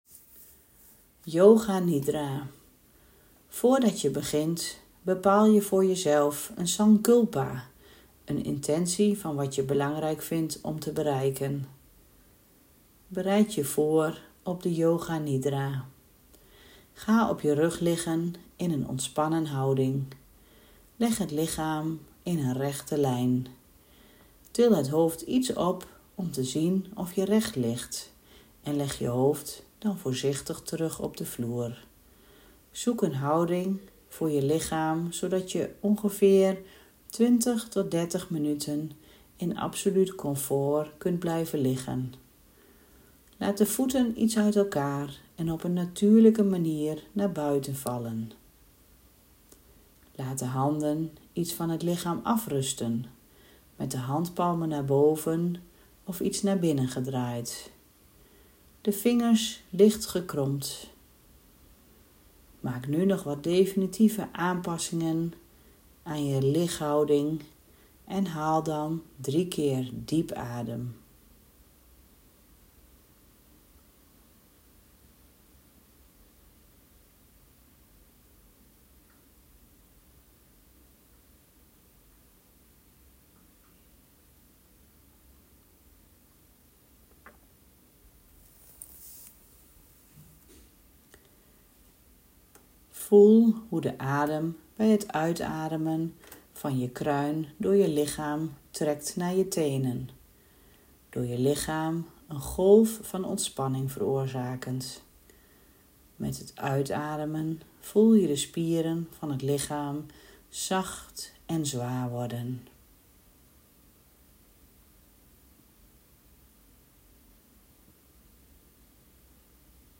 Dit is een slaap yoga oefening, die je eens kunt luisteren voor je gaat slapen!
Yoga-Nidra.m4a